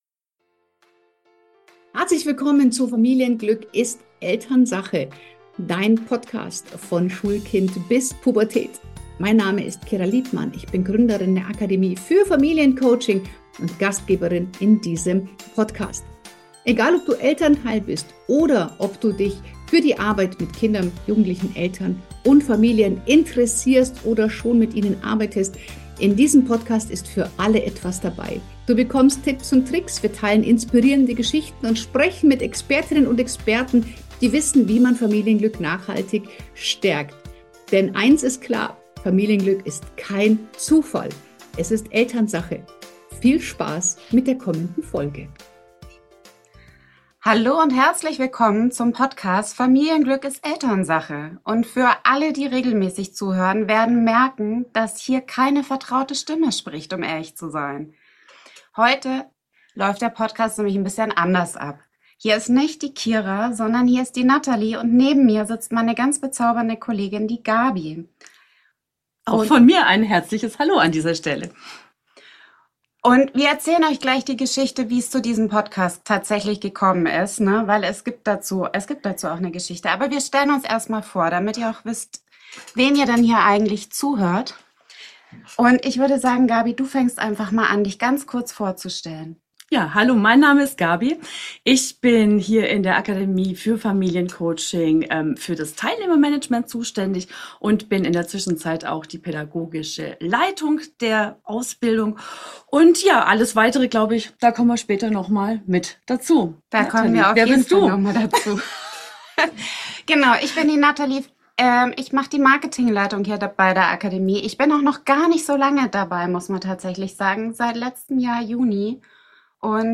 Eine spontane Folge. Echte Gespräche.